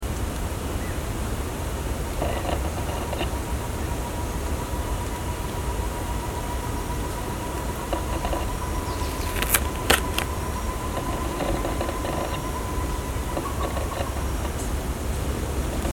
Short-tailed Antthrush (Chamaeza campanisona)
Life Stage: Adult
Location or protected area: Parque Nacional Iguazú
Condition: Wild
Certainty: Observed, Recorded vocal